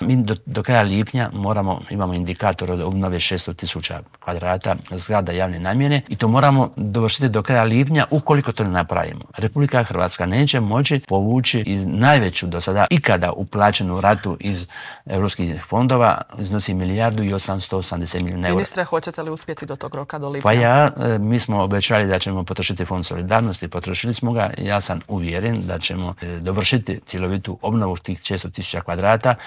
ZAGREB - U nedjelju je obilježena šesta godišnjica zagrebačkog potresa, što se još treba napraviti po pitanju poslijepotresne obnove u Intervjuu tjedna Media servisa pitali smo potpredsjednika Vlade i ministra prostornog uređenja, graditeljstva i državne imovine Branka Bačića.